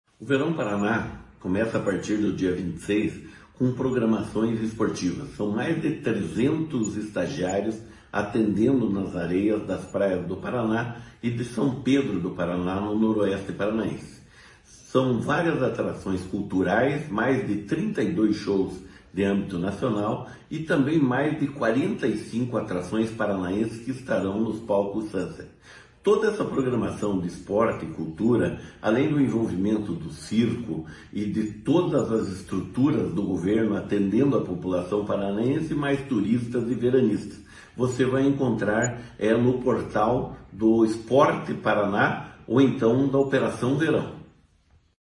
Sonora do secretário do Esporte, Hélio Wirbiski, sobre a programação esportiva do Verão Maior Paraná 2024/2025